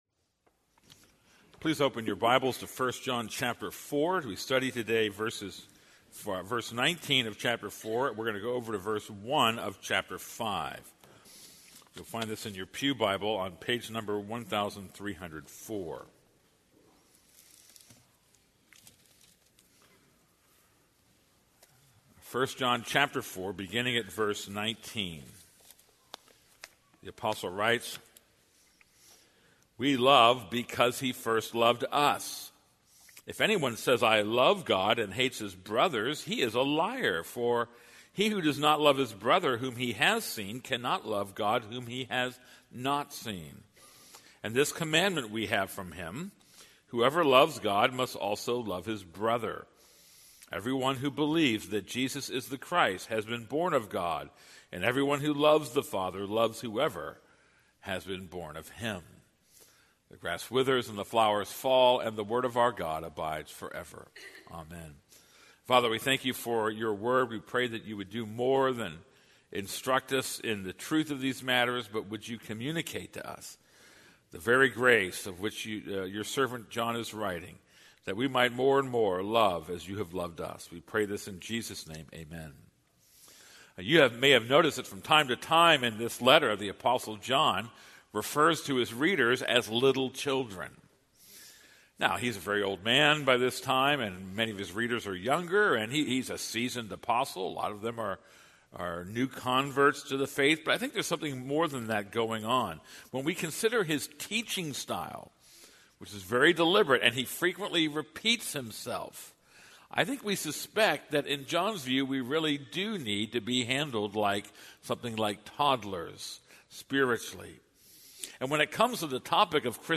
This is a sermon on 1 John 4:19-5:1.